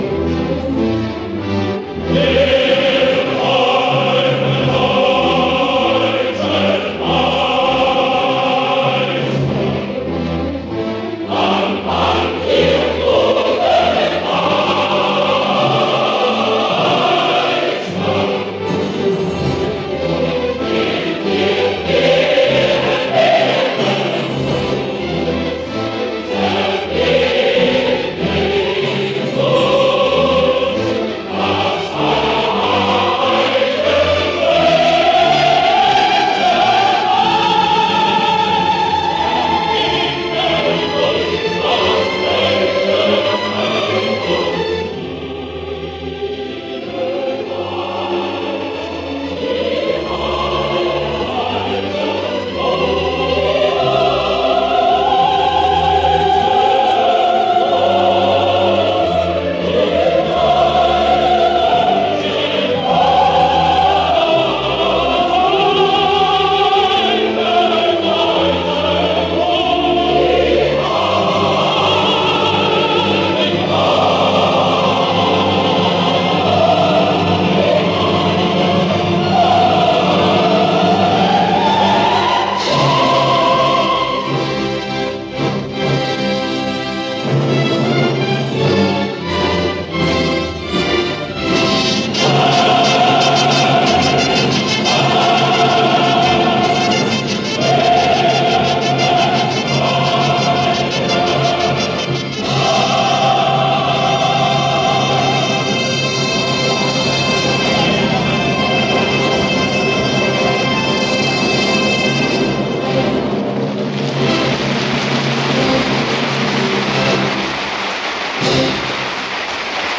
前奏曲 C大调。